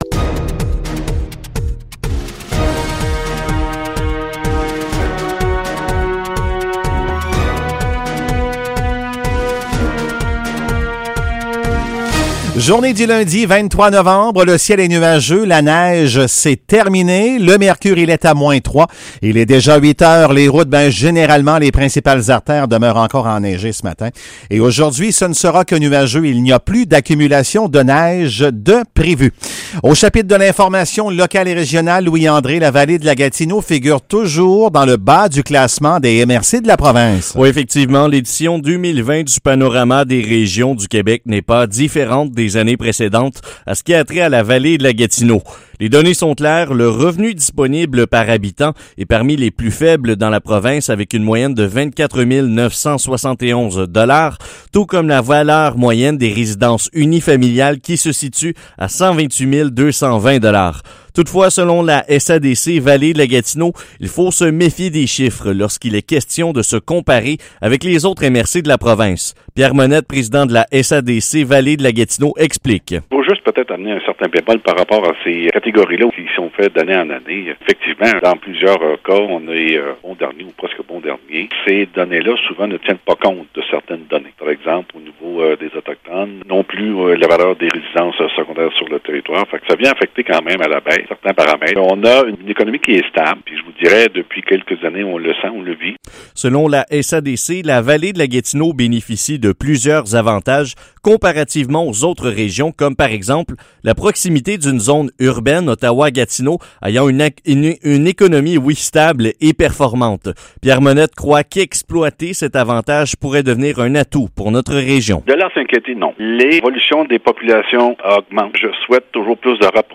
Nouvelles locales - 23 novembre 2020 - 8 h